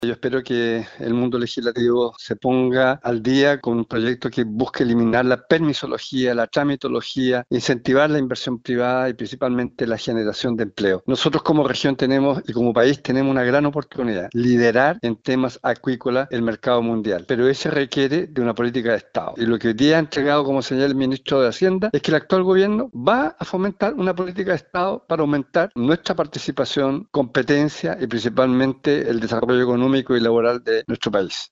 El gobernador de la región de Los Lagos, Alejandro Santana, sostuvo que la iniciativa representa una señal positiva para el desarrollo económico de la zona.